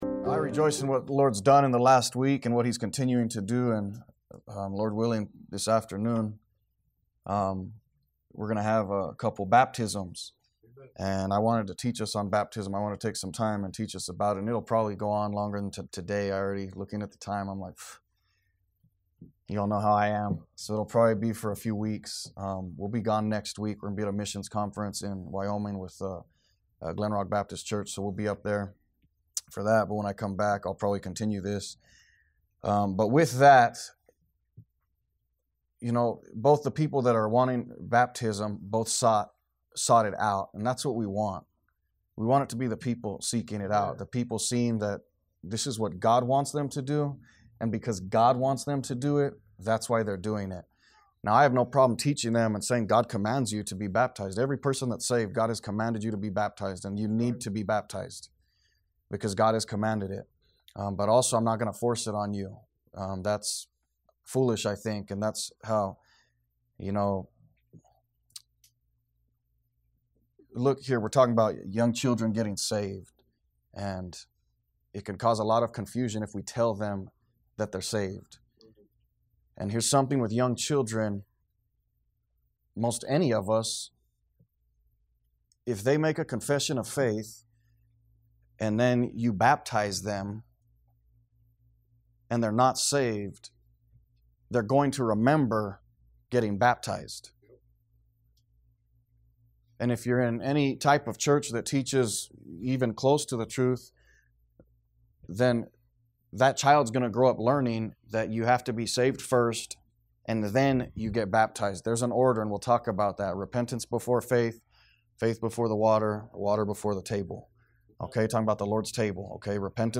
A message from the series "What is a Disciple?."